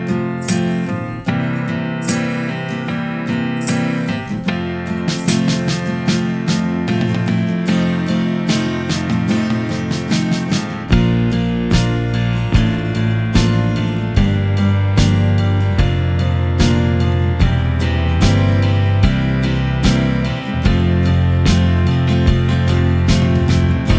No Backing Vocals End Cut Down Pop (1960s) 5:01 Buy £1.50